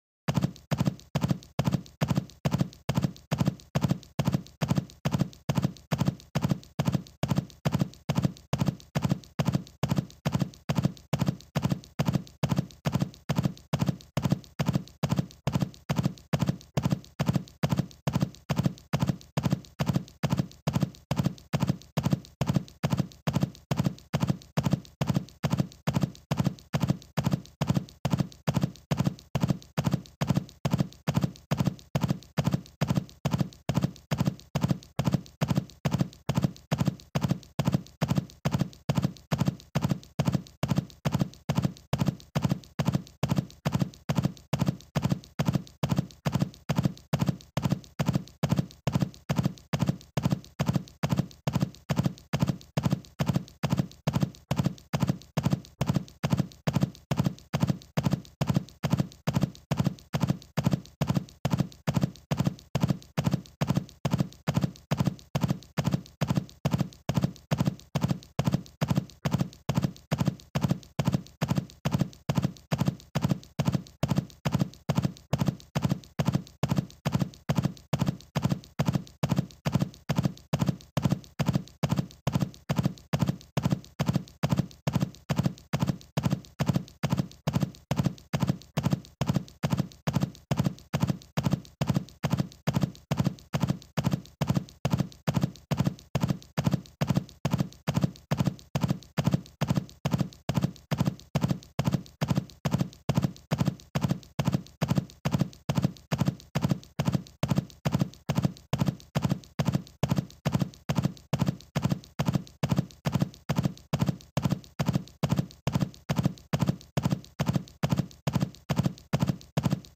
دانلود صدای دویدن اسب – یورتمه اسب 2 از ساعد نیوز با لینک مستقیم و کیفیت بالا
جلوه های صوتی